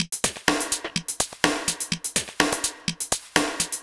Ghetto Tech 02.wav